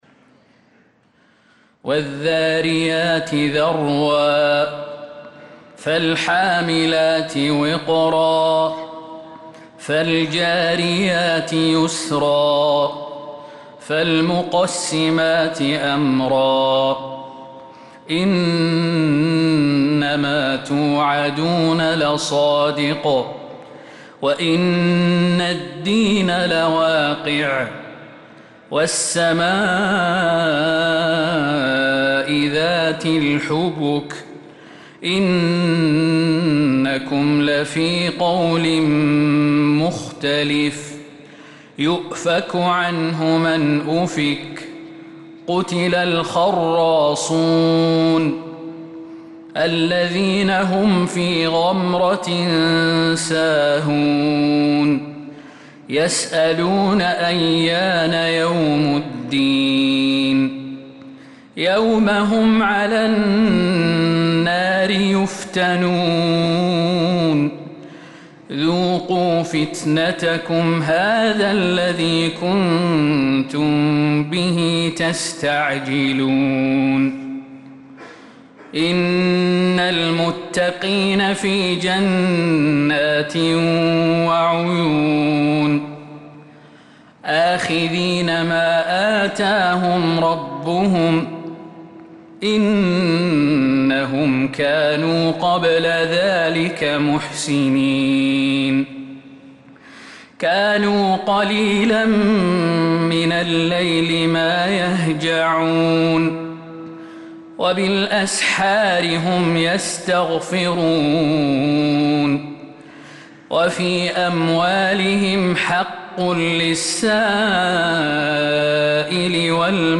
السور المكتملة 🕌 > المزيد - تلاوات الحرمين